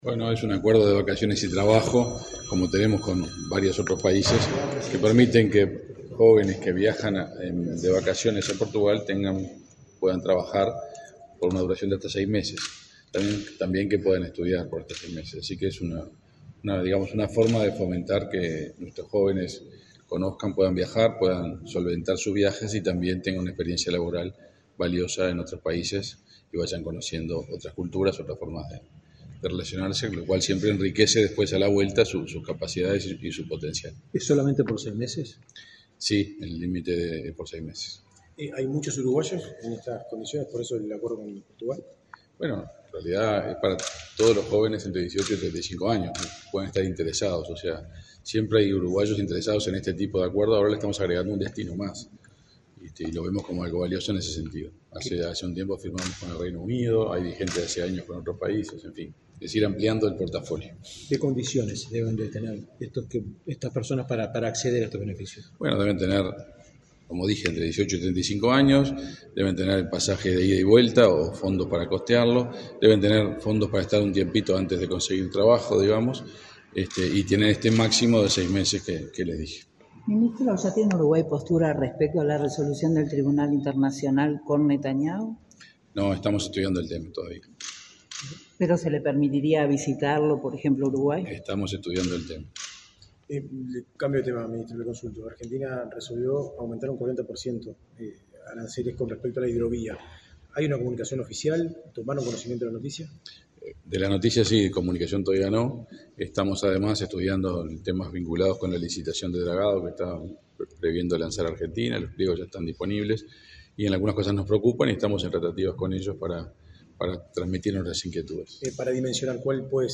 Declaraciones del canciller Omar Paganini a la prensa